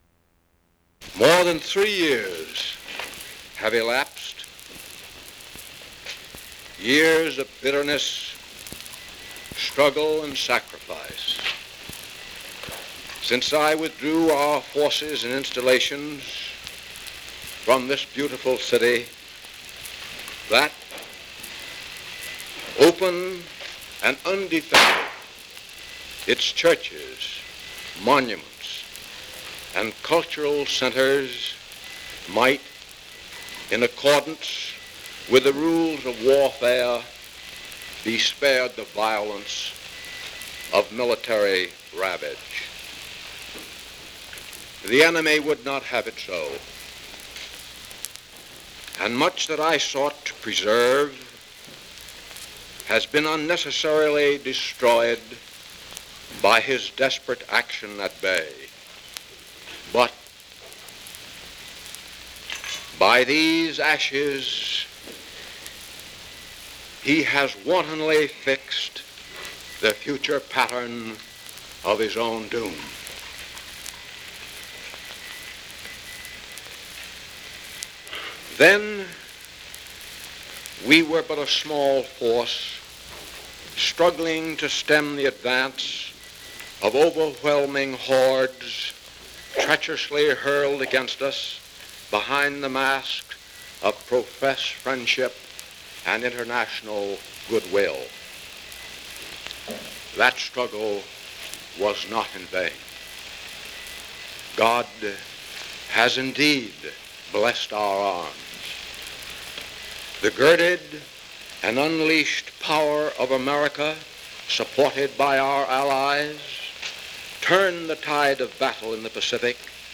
General Douglas MacArthur speaks to Filipinos at Manila about the Philippine liberation during World War II.
Broadcast 1945 April.